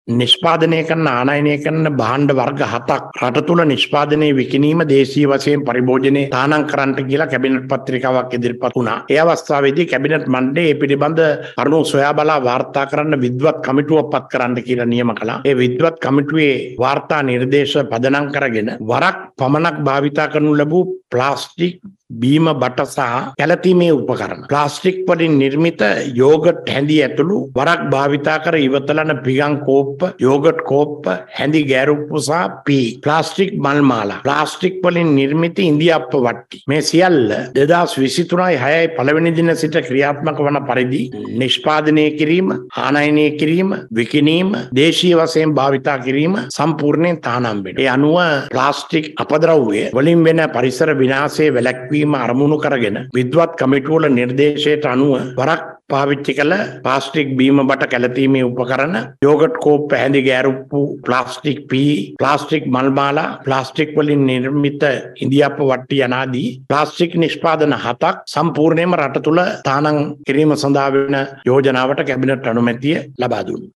මේ සම්බන්ධයෙන් අද පැවති කැබිනට් තීරණ දැනුම් දීමේ මාධ්‍ය හමුවේදී කැබිනට් මාධ්‍ය ප්‍රකාශක බන්දුල ගුණවර්ධන මහතා අදහස් පළ කළා.